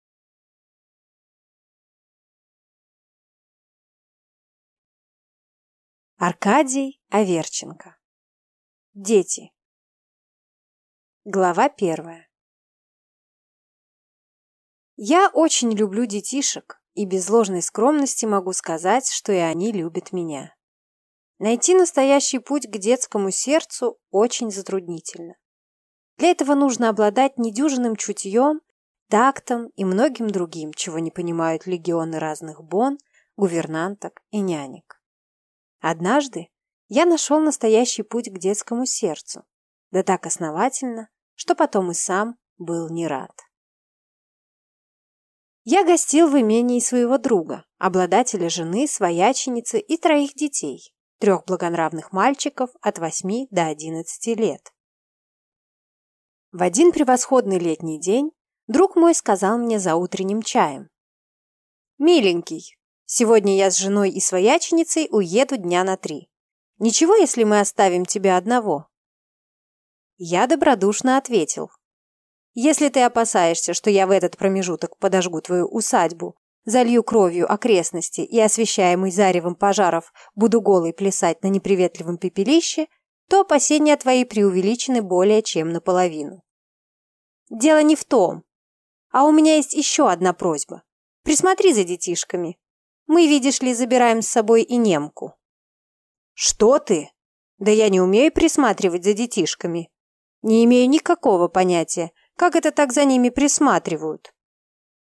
Aудиокнига Дети